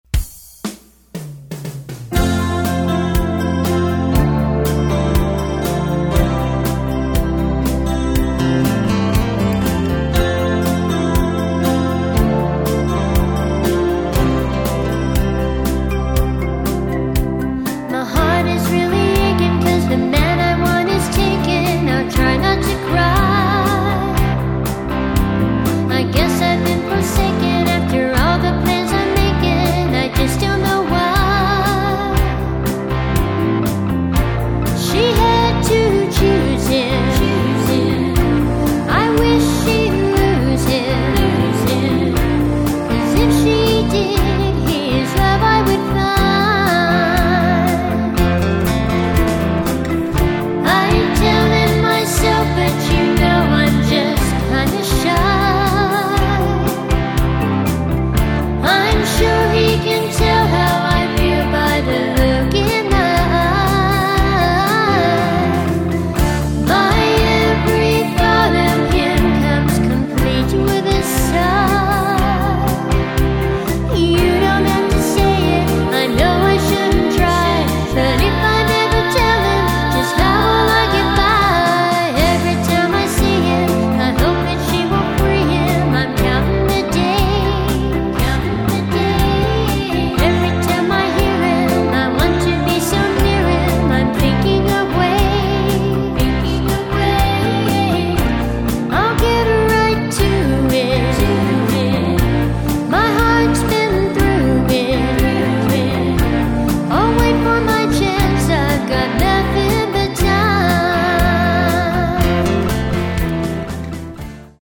Adult Contemporary Originals